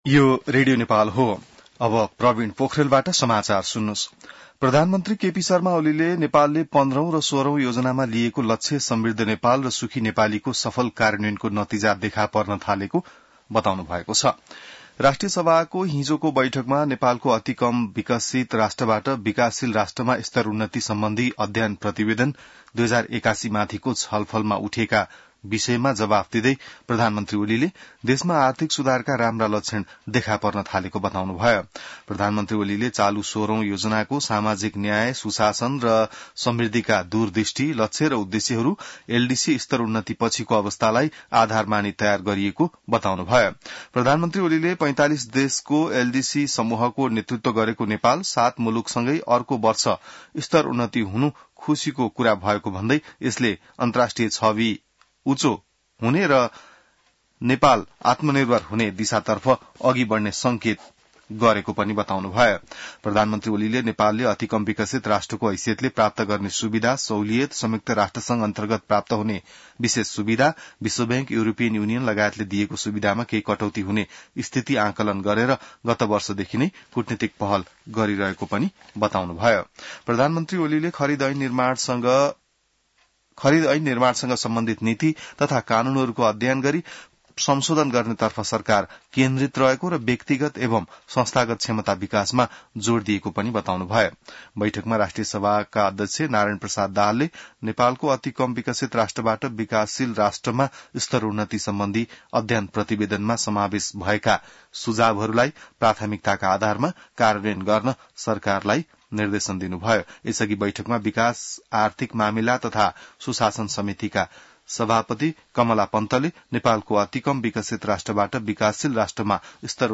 बिहान ६ बजेको नेपाली समाचार : १५ साउन , २०८२